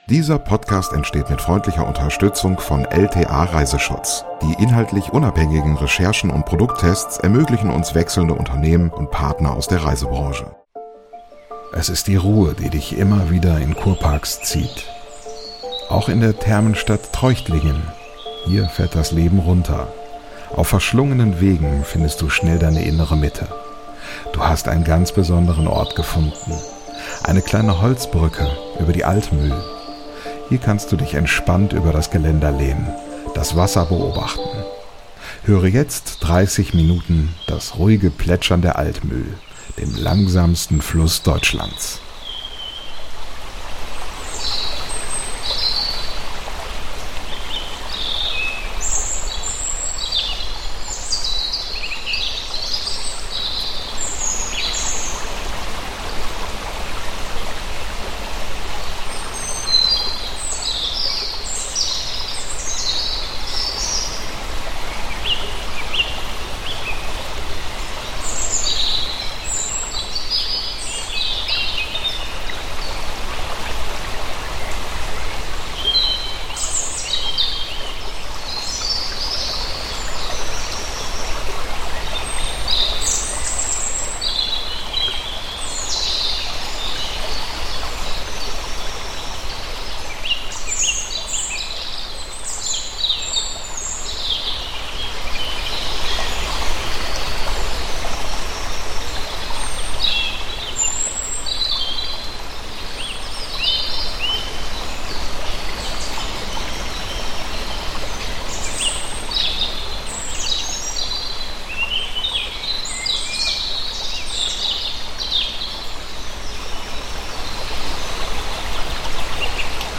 ASMR Fluss Altmühl in Treuchtlingen: Ambient 3D-Sound zum Einschlafen ~ Lieblingsreisen - Mikroabenteuer und die weite Welt Podcast
Du hast einen ganz besonderen Ort gefunden, eine kleine Holzbrücke über die Altmühl.
Höre jetzt 30 Minuten das ruhige Plätschern der Altmühl, dem langsamsten Fluss Deutschlands.